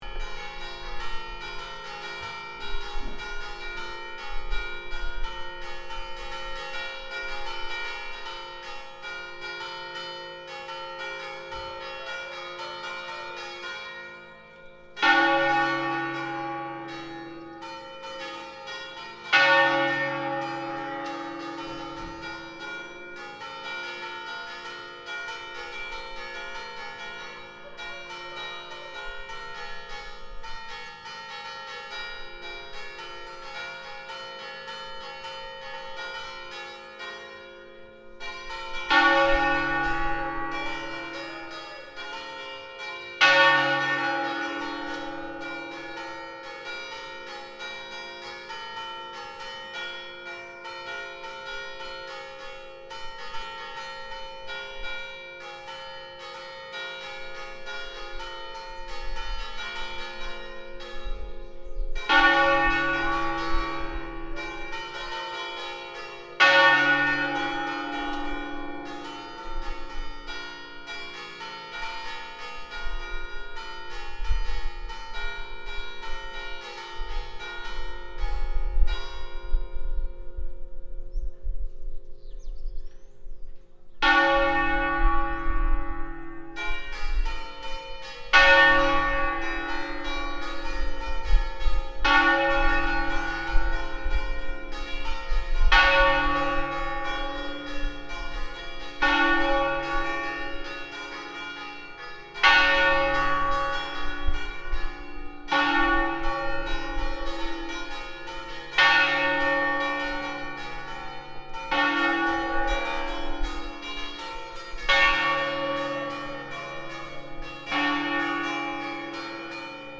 Campane di Pasqua - Città Metropolitana di Torino...
Domenica di Pasqua in un paese del Canavese Romano C.se
Registratore ZOOM H4n
Campane-di-Pasqua.mp3